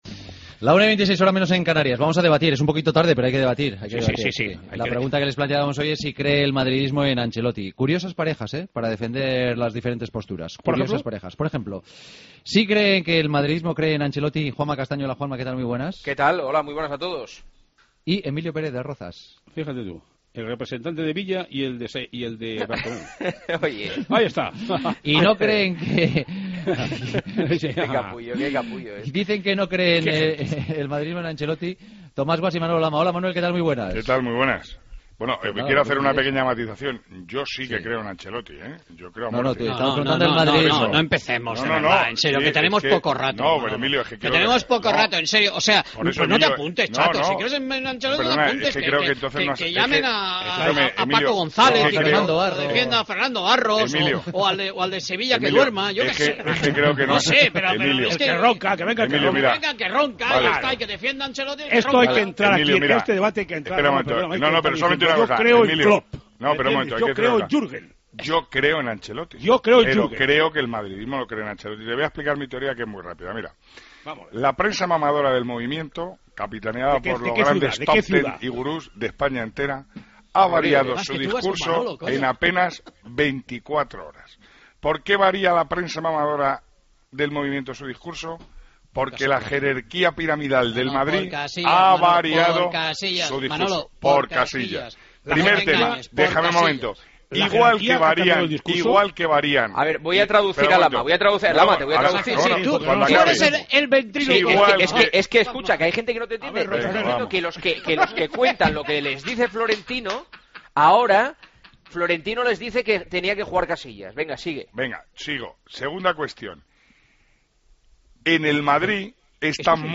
El debate de los jueves: ¿Cree el madridismo en Ancelotti?